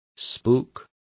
Complete with pronunciation of the translation of spooks.